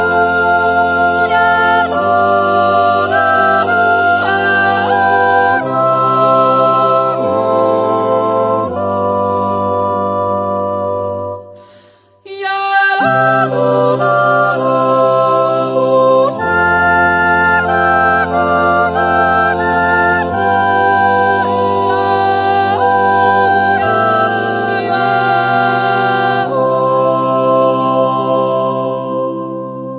Jodellieder, Naturjodel, Ratzliedli